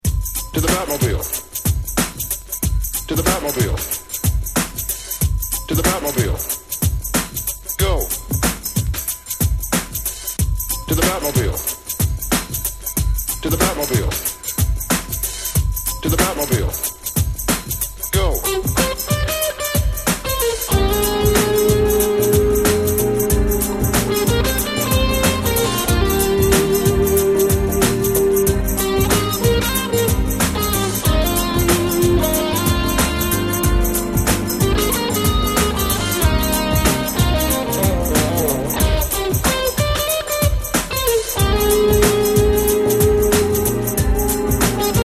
Discomagic系Ground Beat最高峰。
哀愁系ギターの音色が堪りません…格好良すぎる！！